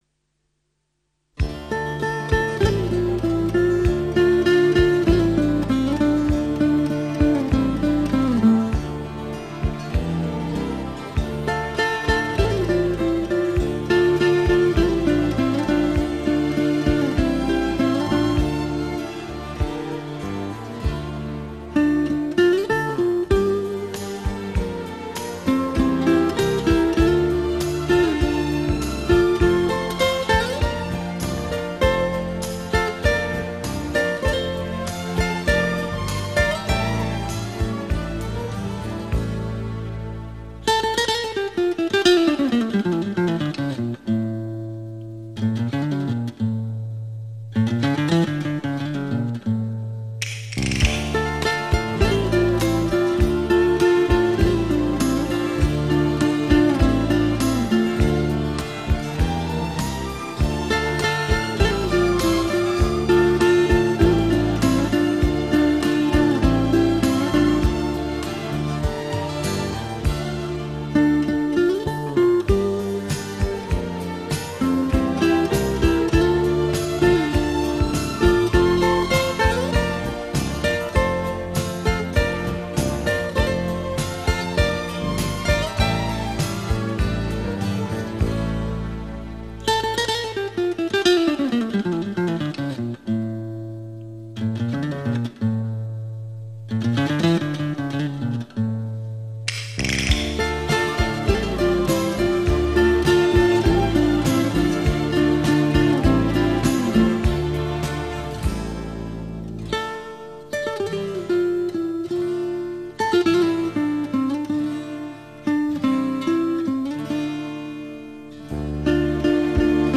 [9/7/2010]【西班牙佛拉门戈吉他曲】--- 悲伤的西班牙（领略吉他的魅力） 激动社区，陪你一起慢慢变老！
间奏时的那一段空寂，那一串响板，每次都能让人感觉这是一种死得其所、浪漫入骨的悲伤.....